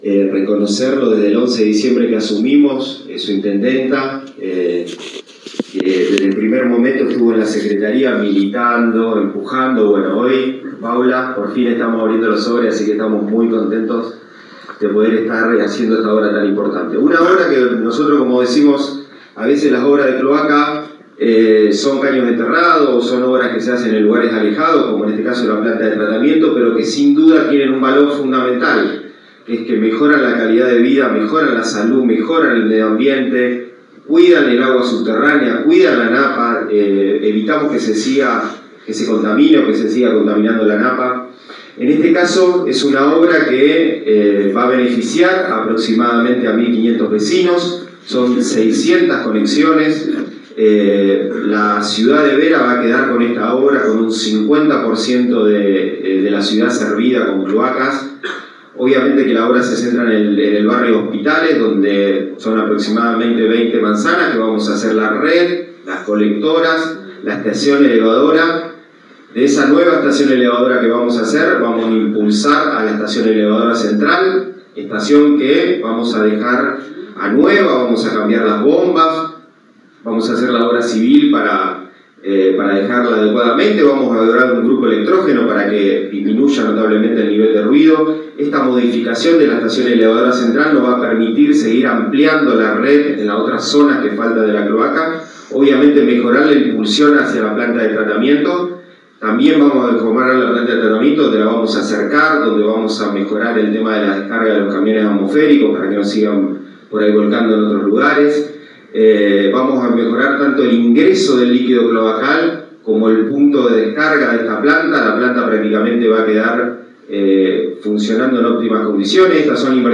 Secretario de Agua y Saneamiento del gobierno provincial, Leonel Marmirolli